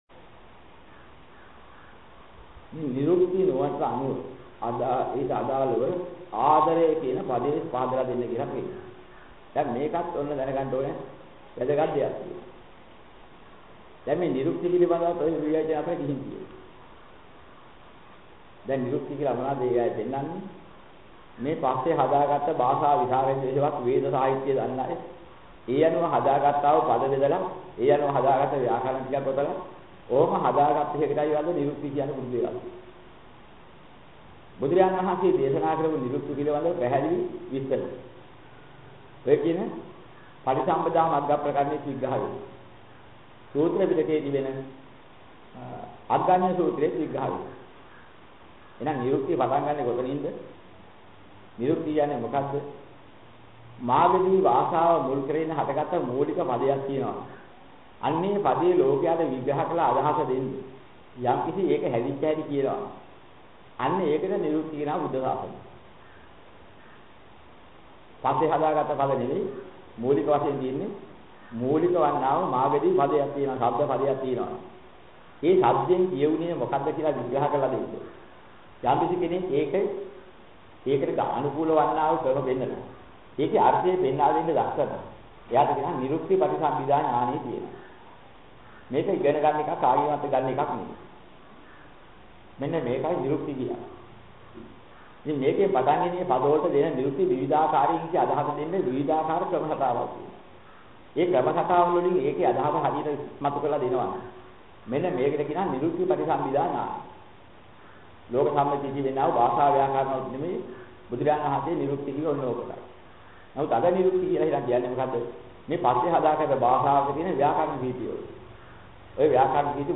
මෙම දේශනාවේ සඳහන් වන ධර්ම කරුණු: